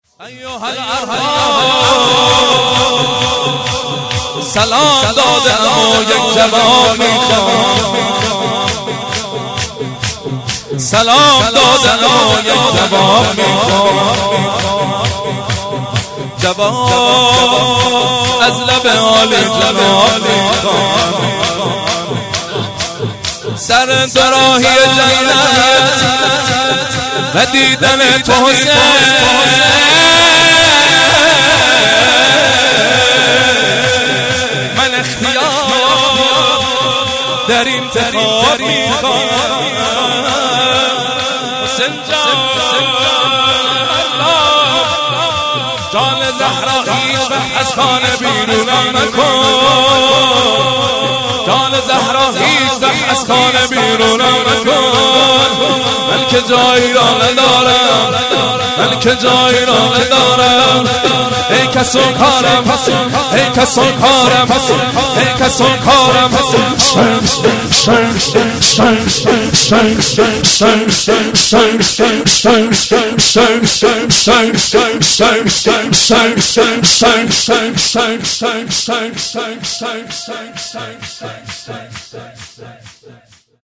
0 0 ذکر و شعر خوانی